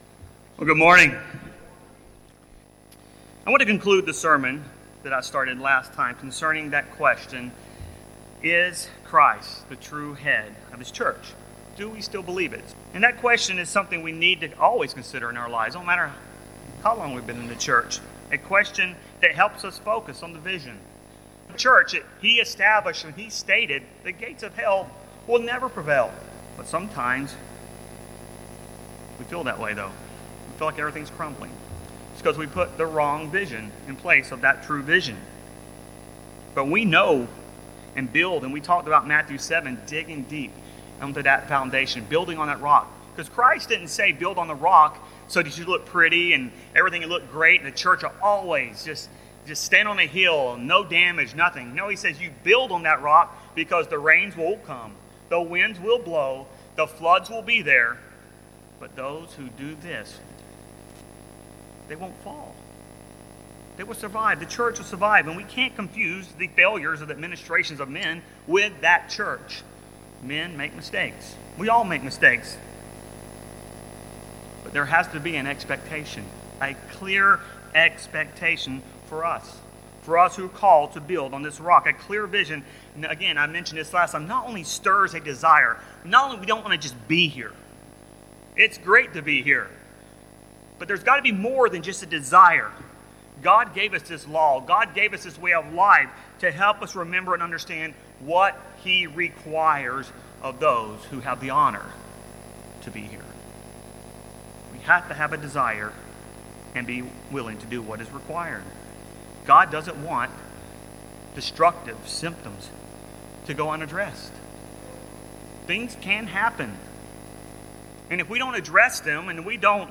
In this concluding sermon, we will examine four of these stumbling block, destructive attitudes, that can develop when we lose sight of the vision set by the True Head of the Church, Jesus Christ.